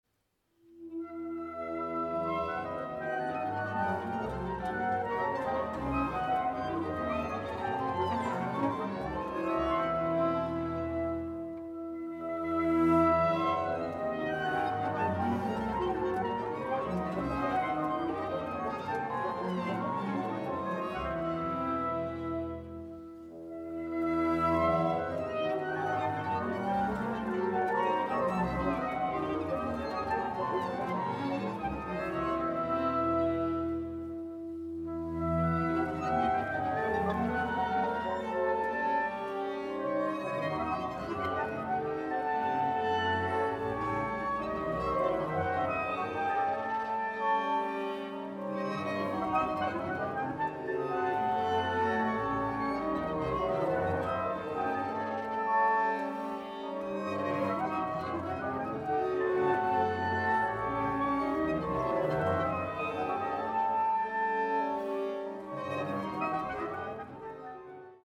large ensemble (17 instruments)